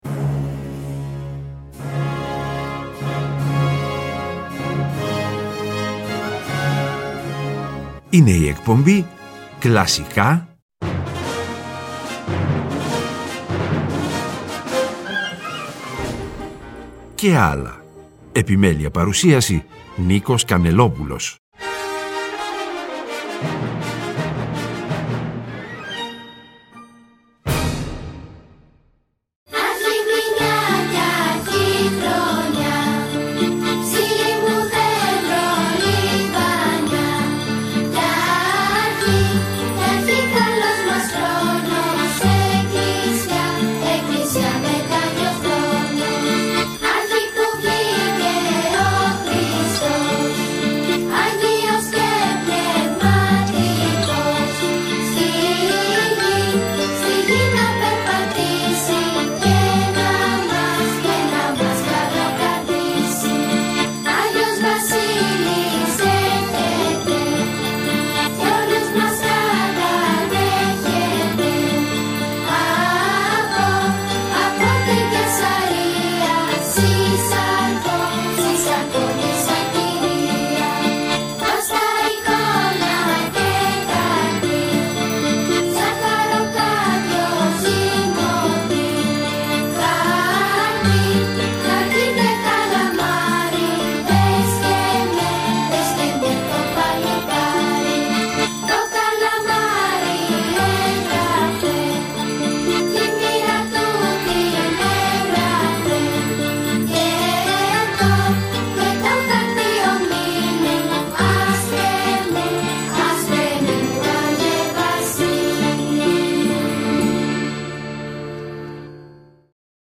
Και στην εκπομπή αυτή, όπως και στη χτεσινή του Σαββάτου 30-12-23, κυριαρχεί η μουσική της οικογένειας Strauss.